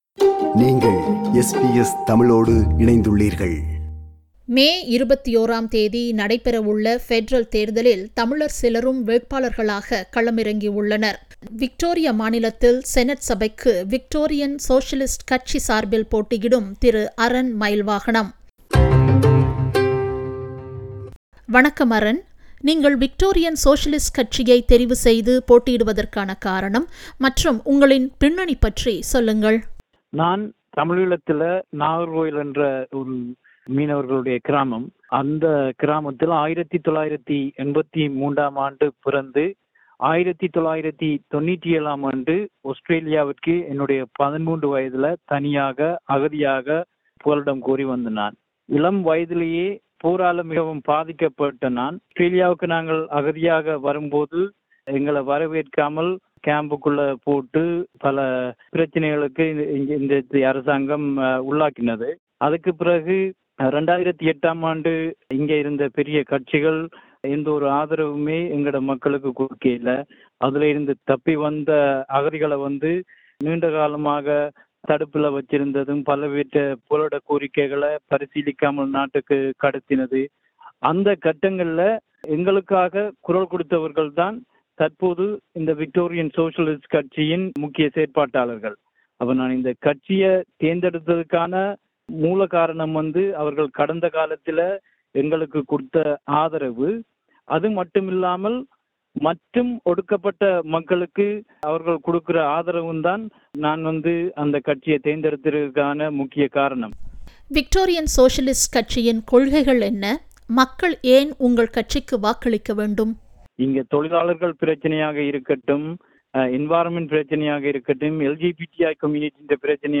Federal election 2022 : Interview